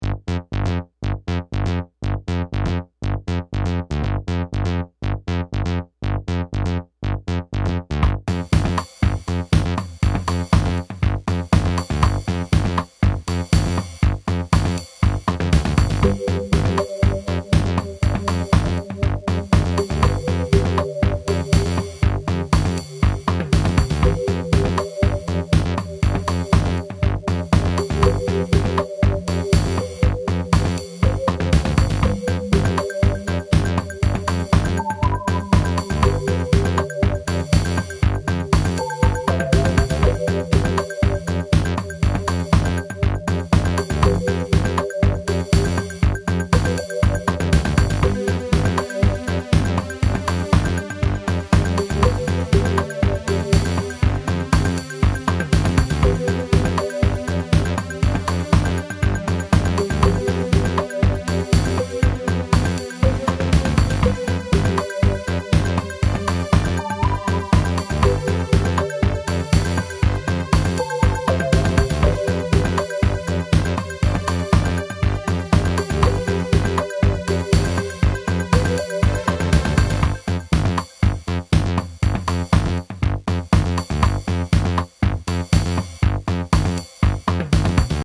ん〜、チープながら何とも言えない独特の味わいを出す音ばかりです。
デジタルシンセは絶対にステレオ出力とばかり思い込んでいたのですがこれはモノラルなんですね。
あれこれ弄くりながらCZだけでサンプルトラックを作ってみました。音は全てプリセット。ドラムはカシオにあわせてRZのサンプルを使ってます。